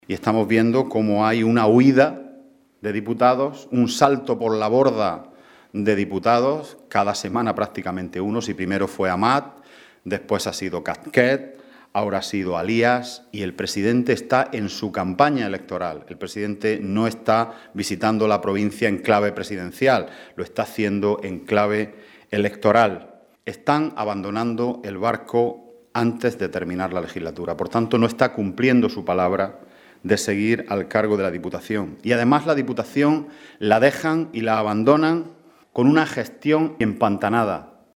El portavoz del Grupo Socialista en la Diputación, Juan Antonio Lorenzo, ha pedido explicaciones al presidente provincial, Javier Aureliano García Molina, después del silencio que éste mantiene pese a conocer, desde hace días, las noticias publicadas en medios nacionales sobre las posibles prebendas recibidas de una empresa mientras fue concejal en el Ayuntamiento de Almería, cuando realizó viajes a Italia y a Argentina, según las facturas publicadas por los medios referidos.